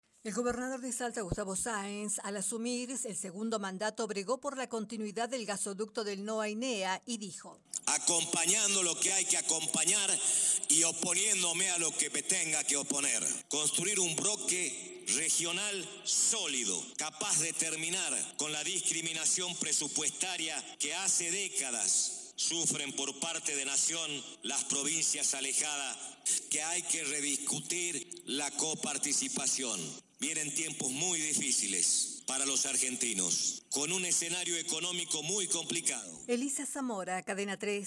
Gustavo Sáenz en su discurso destacó la importancia de la obra pública y señaló que “El Estado no puede ni debe ser una bolsa de trabajo”.